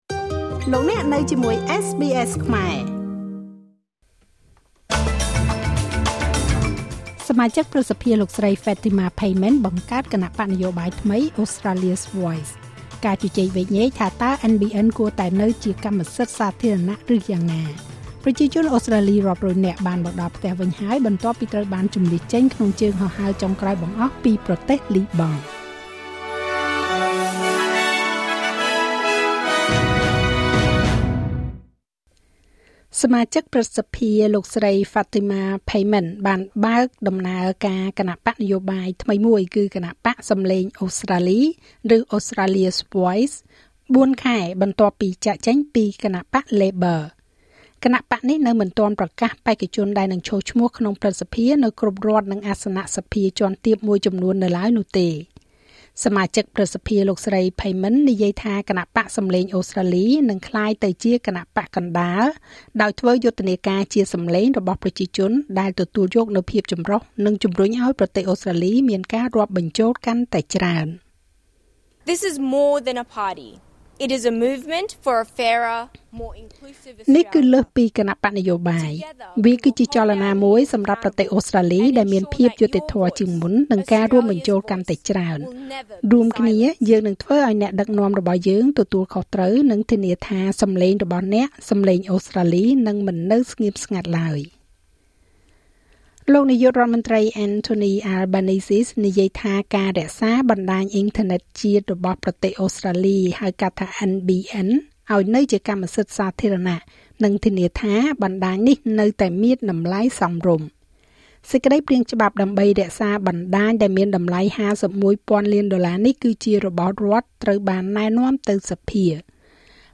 នាទីព័ត៌មានរបស់SBSខ្មែរ សម្រាប់ ថ្ងៃពុធ ទី៩ ខែតុលា ឆ្នាំ២០២៤